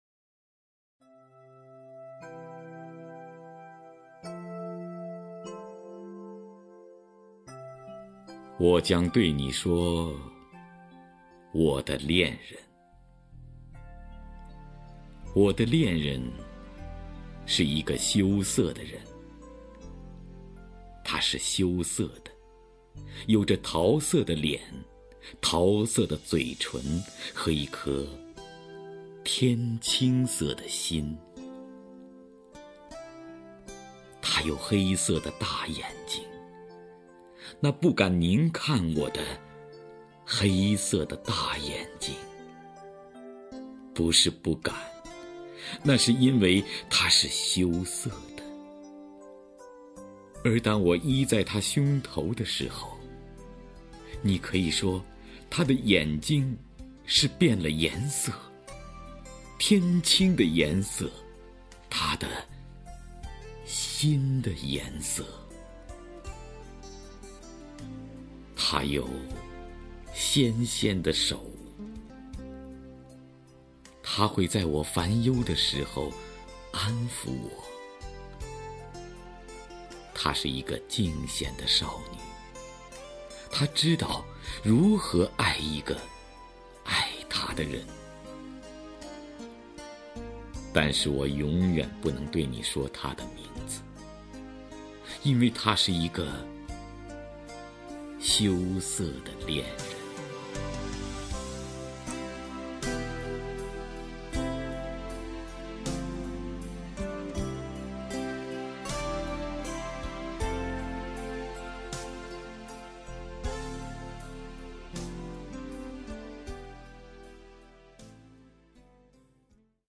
首页 视听 名家朗诵欣赏 徐涛
徐涛朗诵：《我的恋人》(戴望舒)